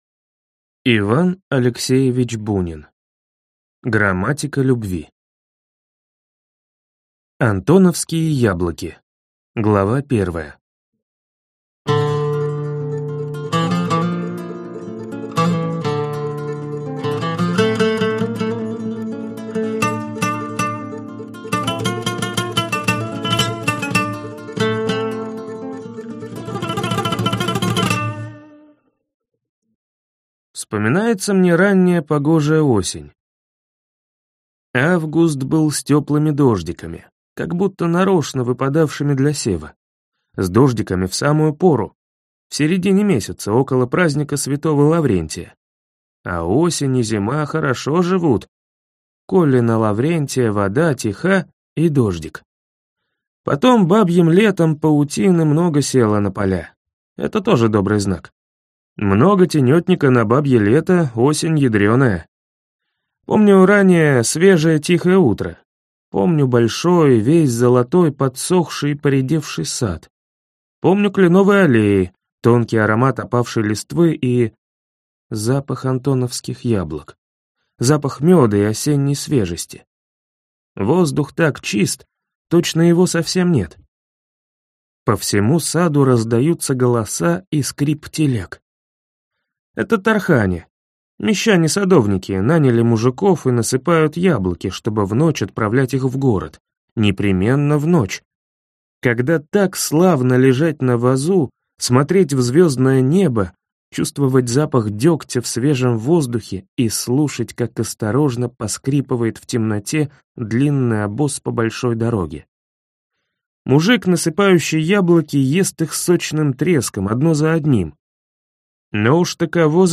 Аудиокнига Грамматика любви | Библиотека аудиокниг